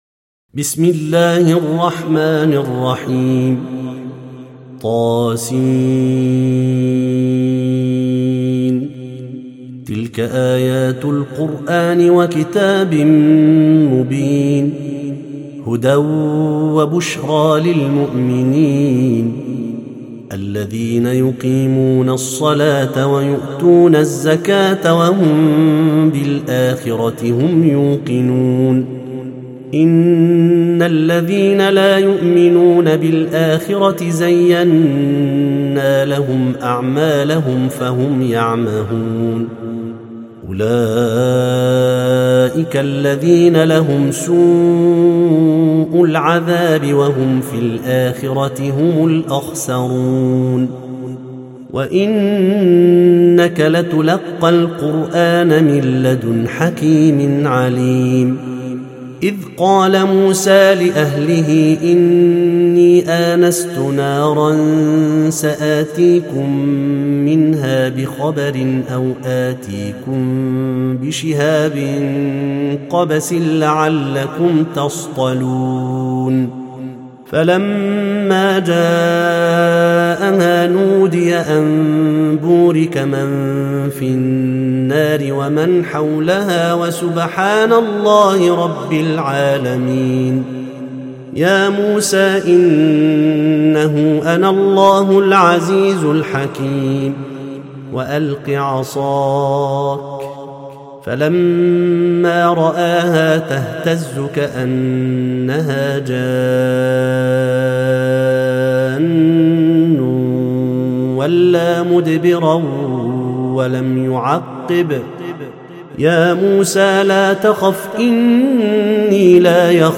سورة النمل - المصحف المرتل (برواية حفص عن عاصم)
التصنيف: تلاوات مرتلة